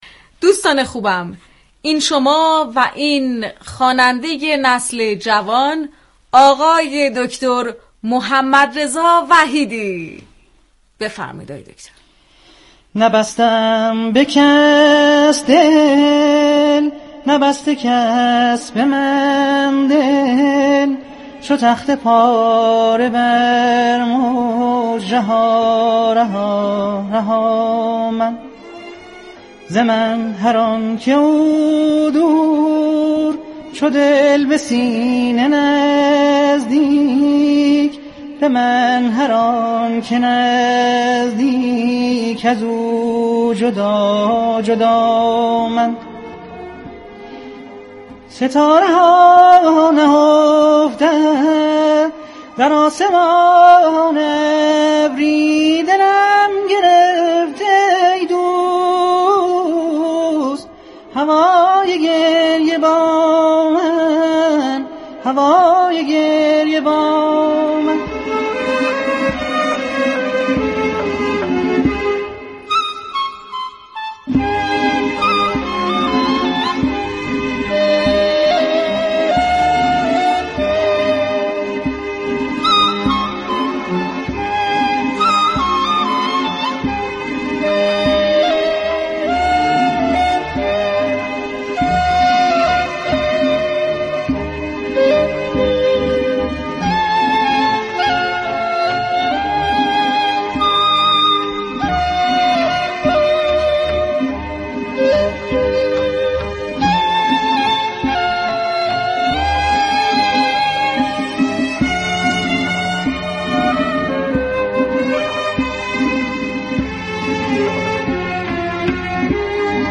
نخبه جوان كشورمان در این گفتگو از آرزو كودكی خود گفت، خوانندگی و آوازخوانی را آرزوی كودكی خود دانست و برای مخاطبان بخش هایی از تصنیف «نبسته ام به كس دل» را برای مخاطبان اجرا كرد.